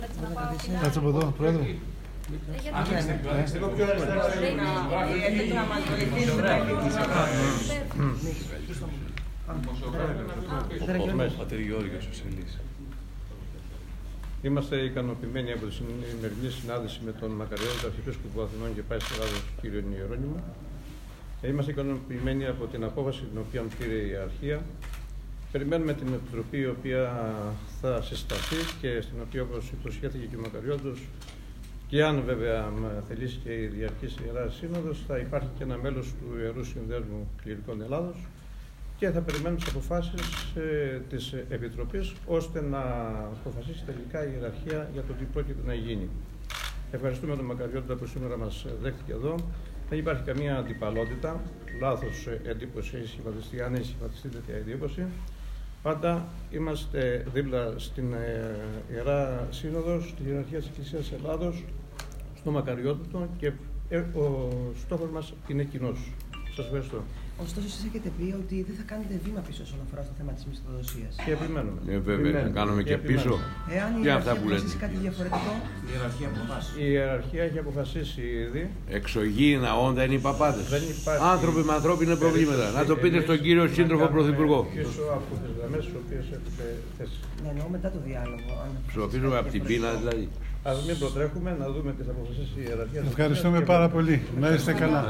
Παραθέτουμε ηχητικά αποσπάσματα των πρώτων δηλώσεων μετά το πέρας της συνάντησης Αρχιεπισκόπου Ιερωνύμου και Ιερού Συνδέσμου Κληρικών Ελλάδος, όπως τις κατέγραψε το Πρακτορείο Εκκλησιαστικό Εισήδεων ΟΡΘΟΔΟΞΙΑ: